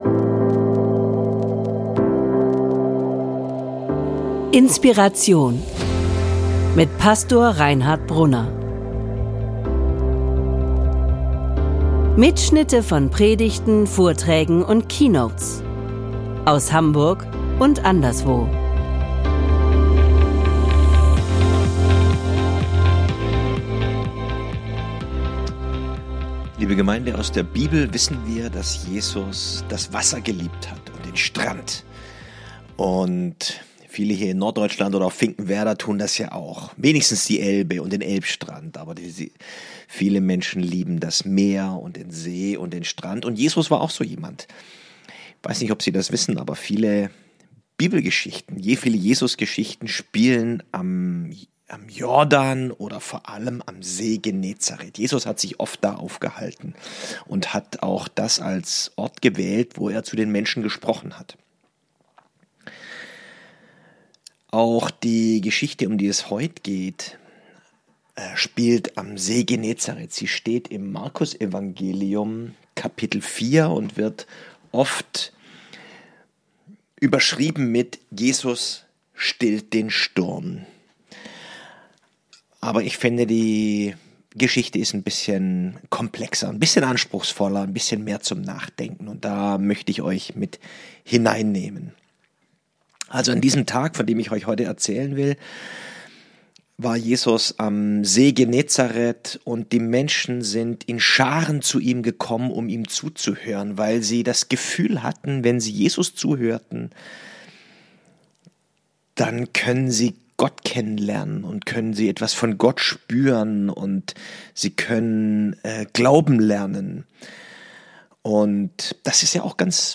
Jeder von uns kennt Stürme im Leben und vielleicht steckst Du gerade mittendrin. In dieser Predigt geht es um eine ganz wunderbare Bibelgeschichte die uns helfen kann, unsere Stürme des Lebens anders zu meistern.